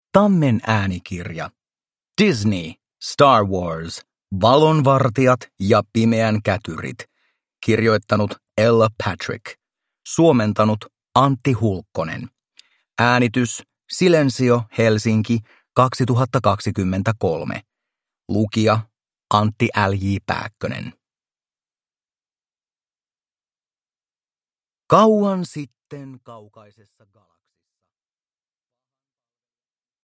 Star Wars. Valon vartijat ja pimeän kätyrit – Ljudbok – Laddas ner